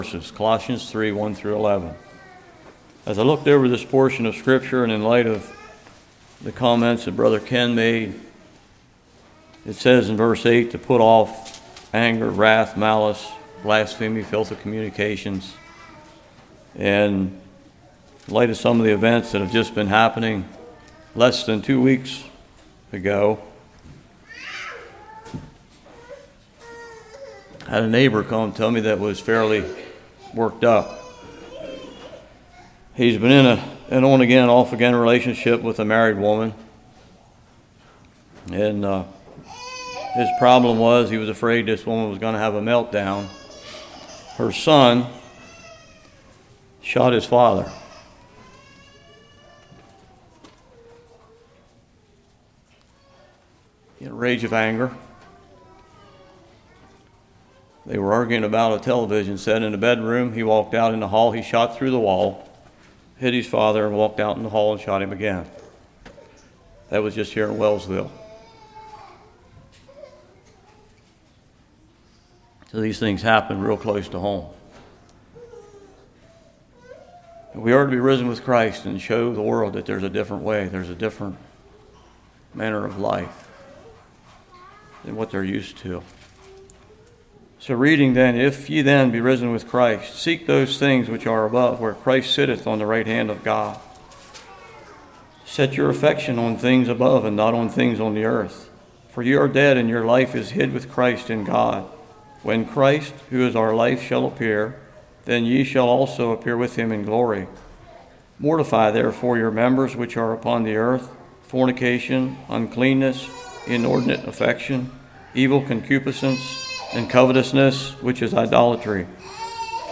Colossians 3:1-11 Service Type: Morning Sins of body